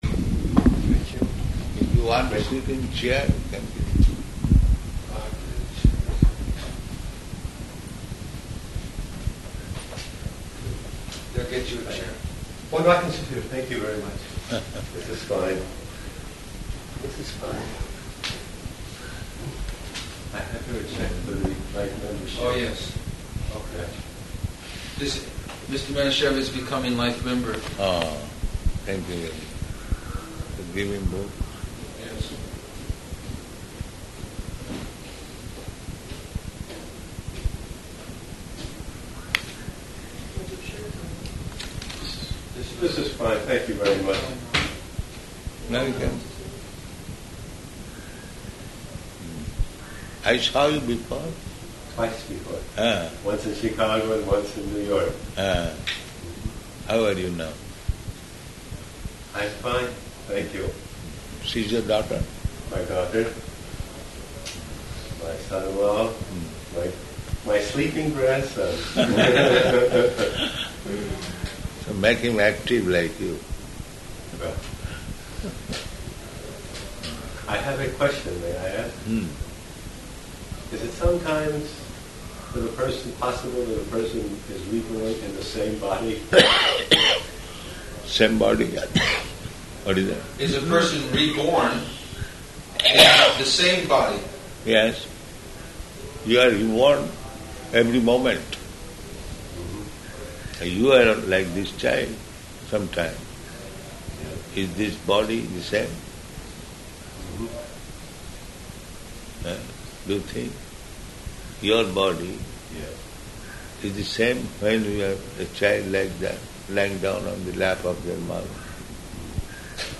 Room Conversation
Location: Philadelphia